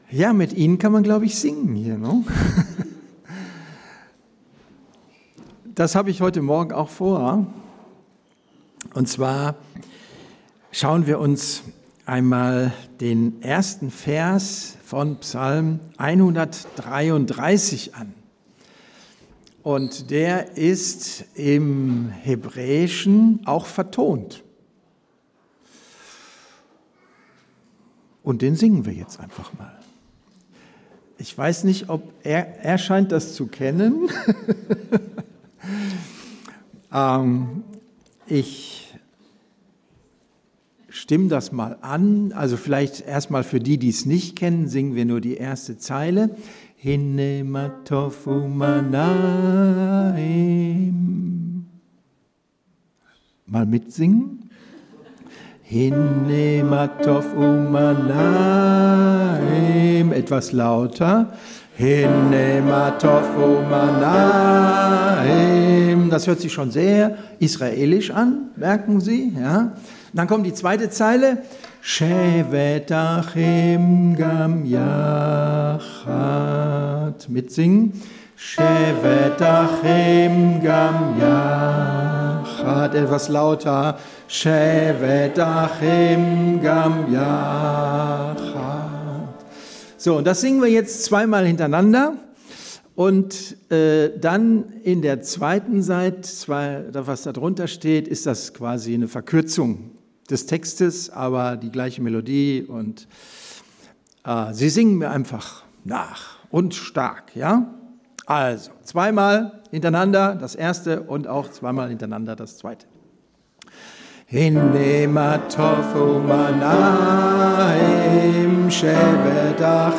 Predigten Online